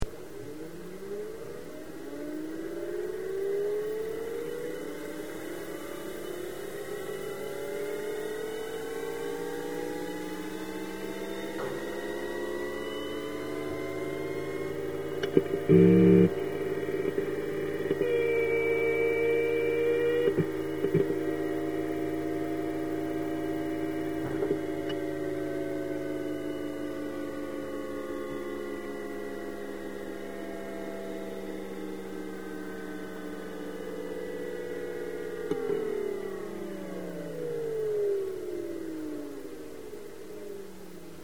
Sounds of my Suns: A MP3 of one of the HDDs spinning up